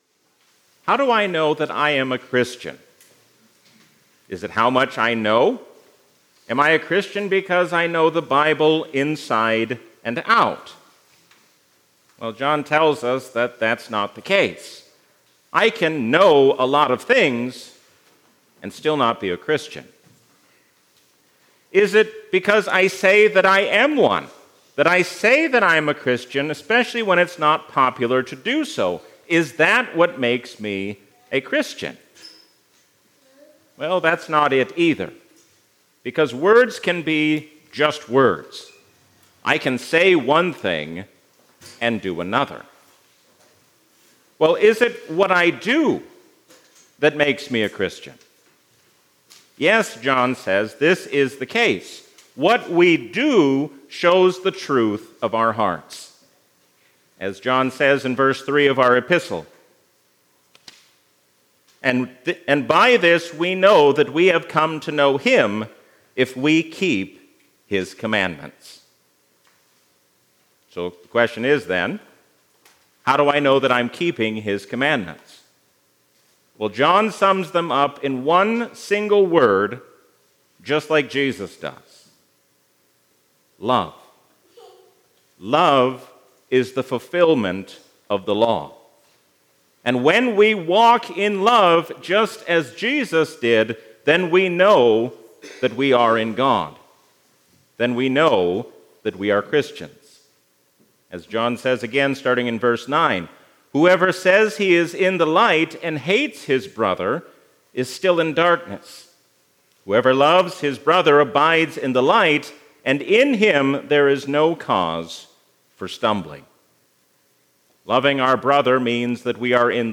A sermon from the season "Easter 2025." Why should we insist on our own way when we have been made one in Christ?